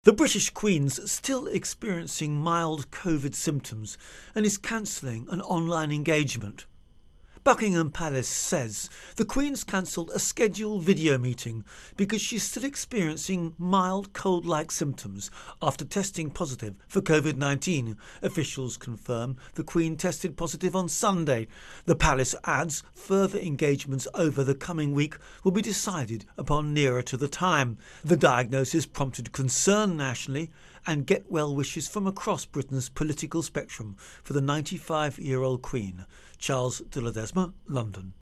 Virus Outbreak-Britain-Queen Intro and Voicer